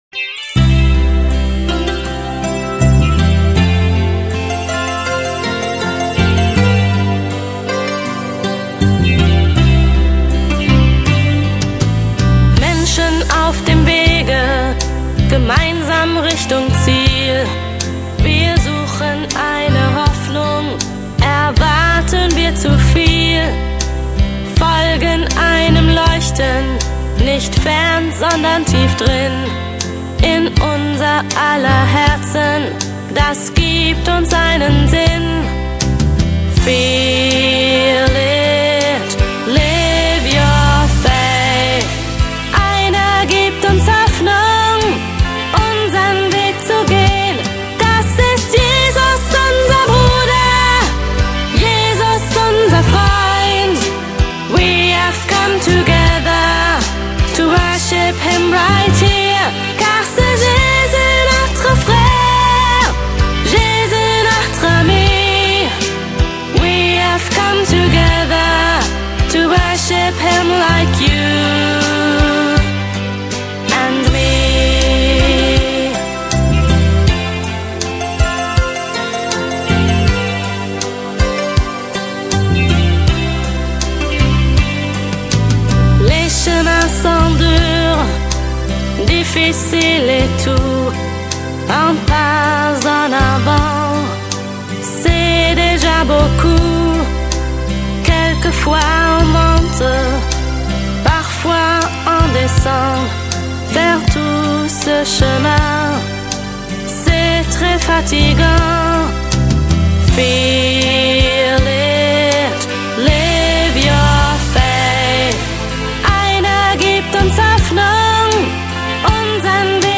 Häufig verwendet wird auch synonym der Begriff "Sacro-Pop".